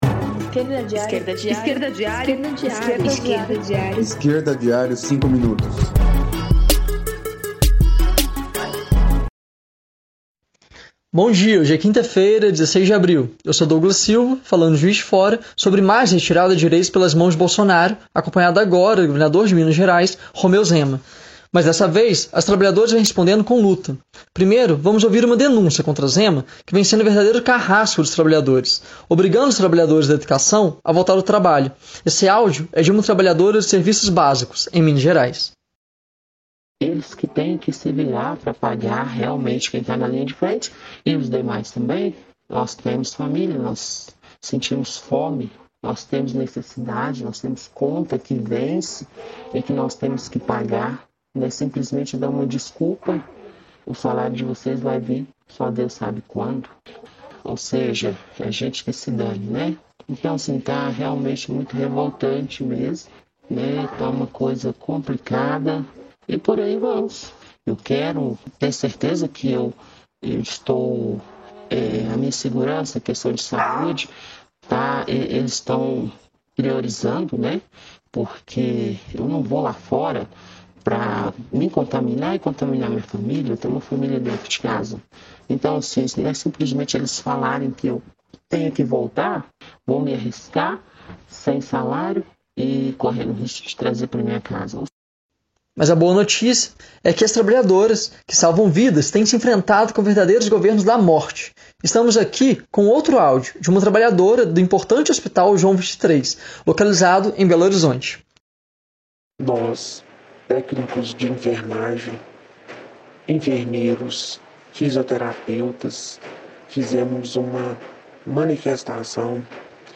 No programa de hoje, denúncias do governo Zema e a luta dos trabalhadores do Hospital João XXIII em BH-MG, com áudios dos que paralisaram por melhores condições de trabalho.